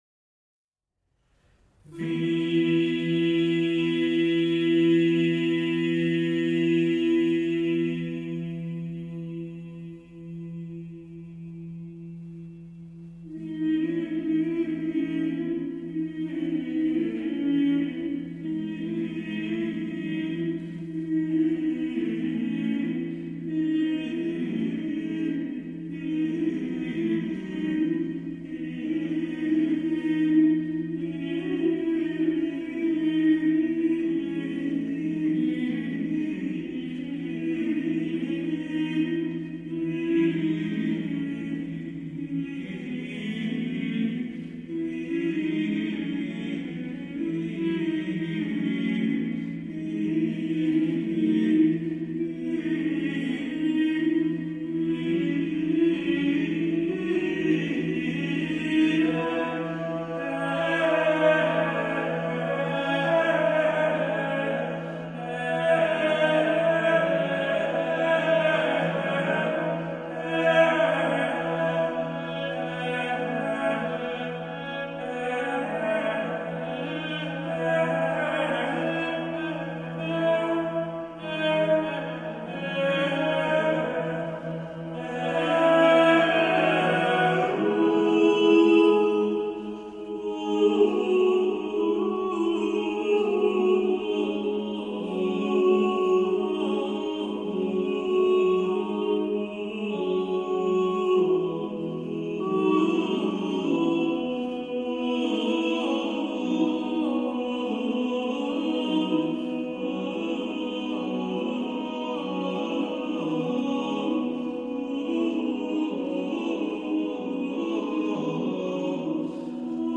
One of these is called organum, and the differences in musical style are an indication of changes in how music was made in the 12th century.
In comparison with Gregorian chant, this music obscures the words that are being sung—this tells us that something else is more important for people who created this music than just the words.